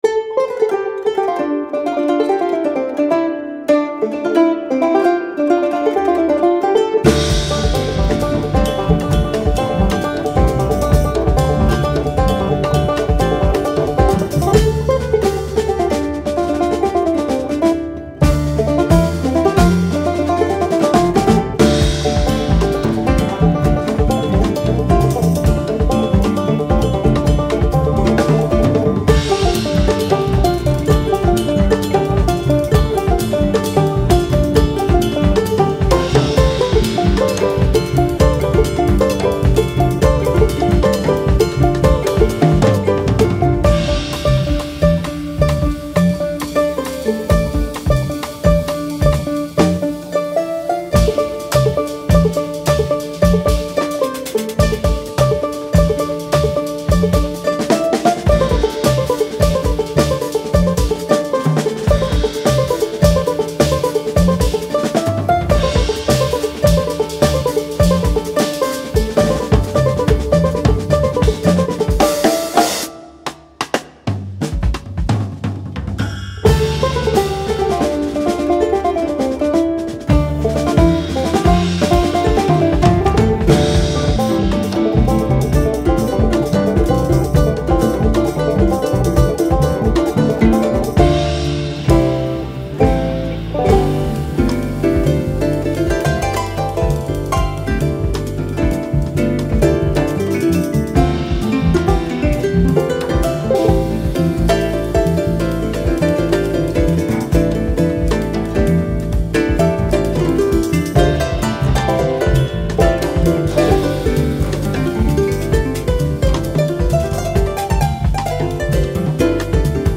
banjo
harpe
batterie